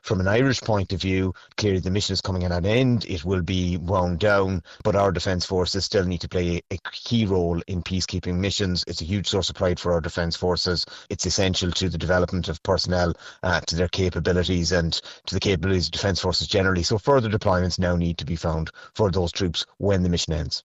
Sinn Féin Foreign Affairs spokesperson Donnchadh Ó Laoghaire says they must be given a new mission: